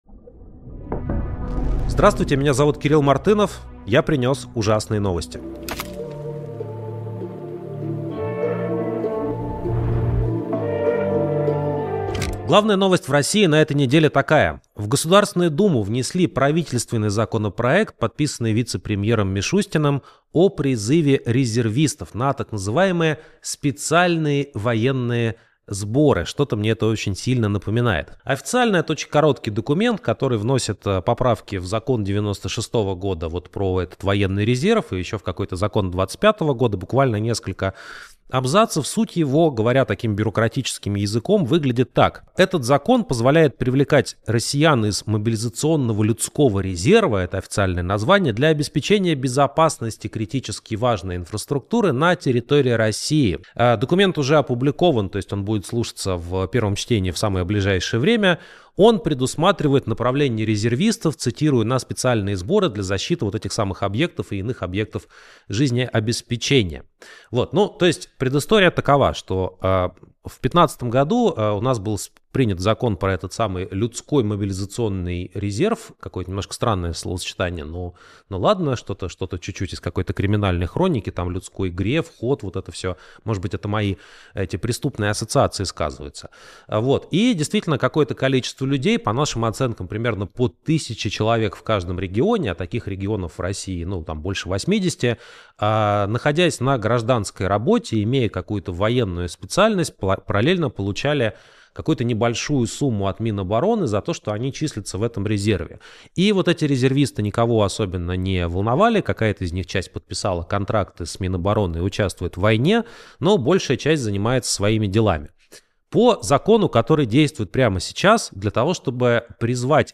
Эфир ведёт Кирилл Мартынов